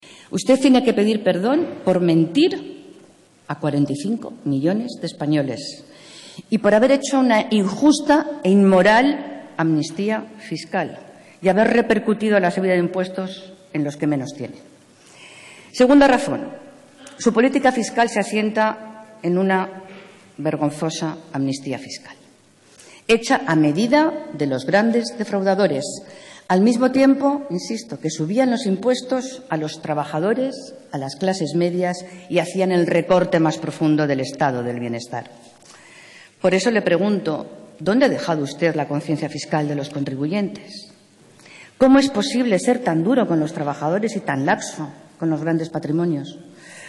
Inmaculada Rodríguez Pinero interpela a Montoro sobre su política fiscal. Pleno del Congreso de los Diputados 17/04/2013